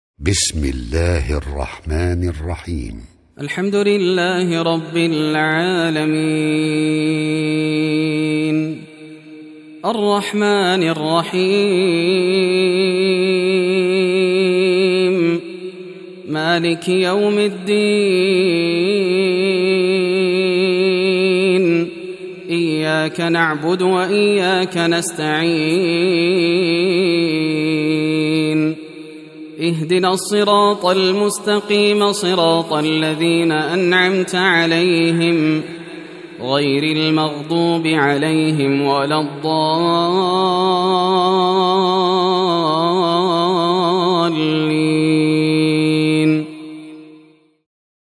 المصحف المرتل